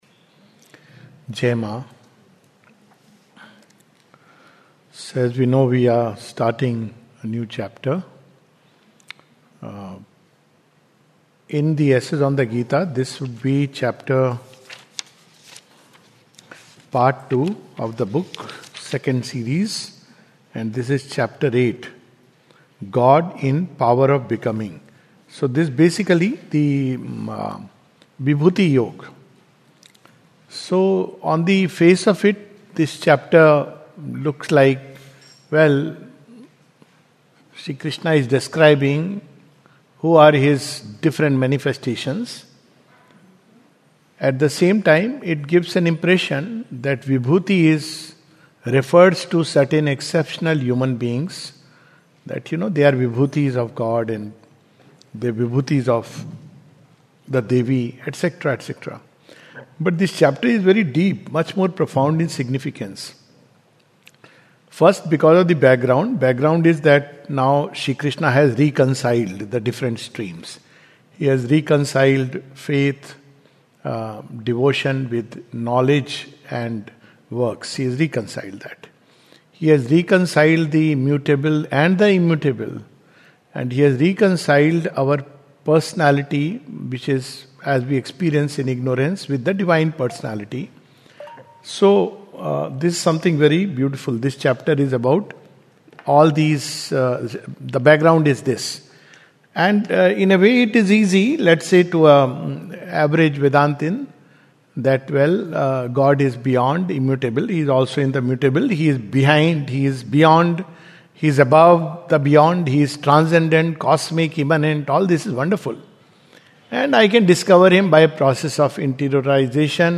This is a summary of Chapter 8 of the Second Series of "Essays on the Gita" by Sri Aurobindo. Talk
recorded on July 30, 2025 at the Savitri Bhavan, Auroville